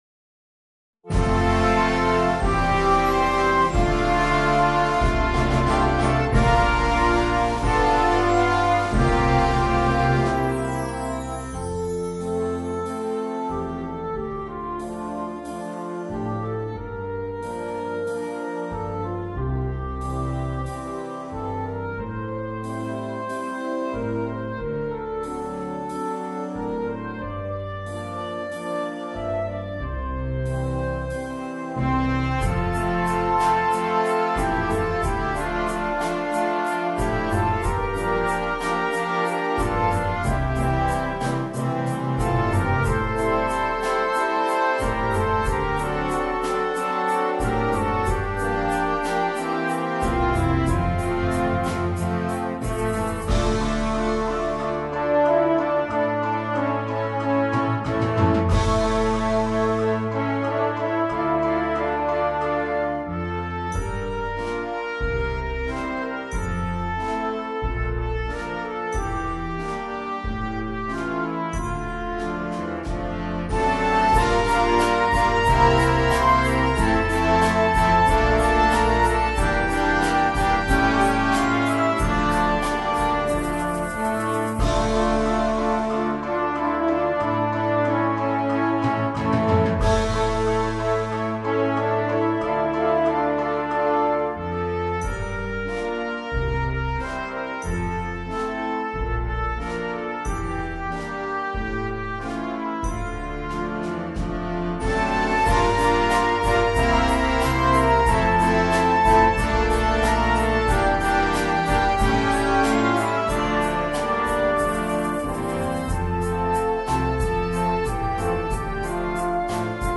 con Drum Set moderno